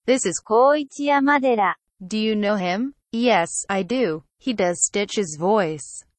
Conversation Dialog #1: